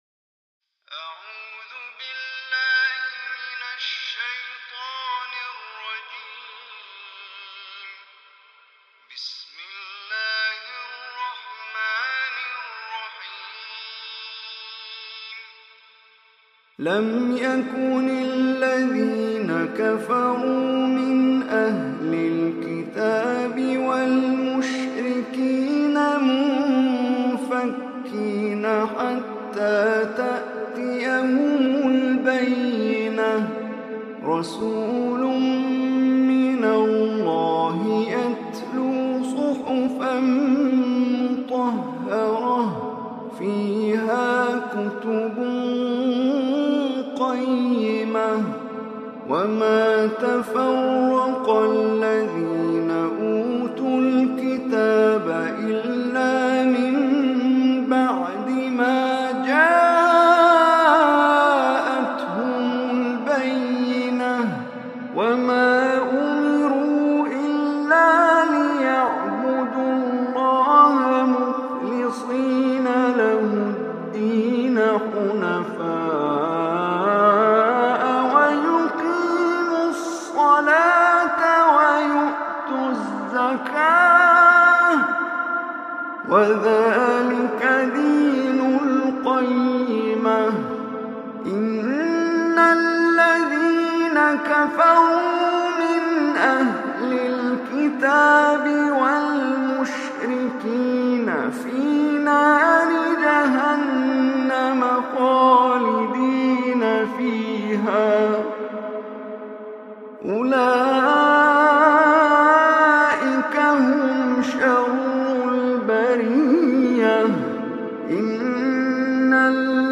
Surah al-Bayyinah Recitation by Omar Hisham Arabi
Surah al-Bayyinah is 98 surah of Holy Quran. Listen or play online mp3 tilawat / recitation in Arabic in the beautiful voice of Omar Hisham Al Arabi.